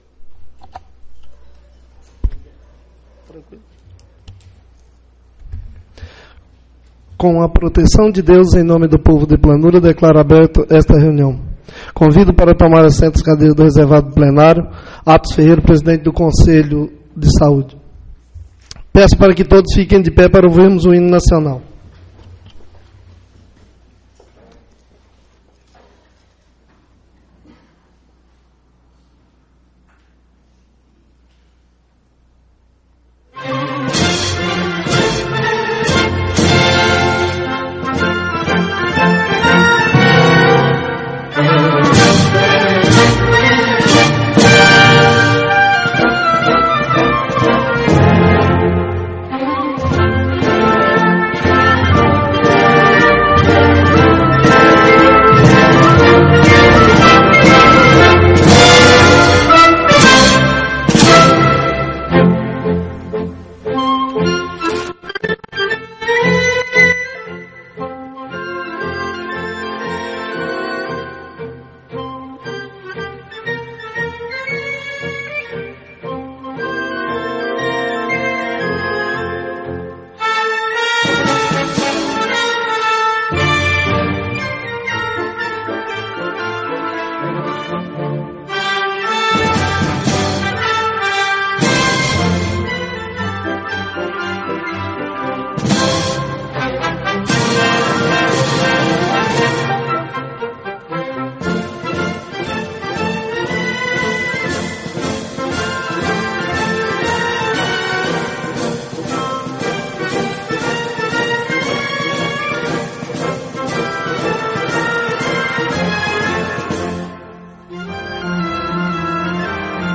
Sessão Ordinária - 18/09/17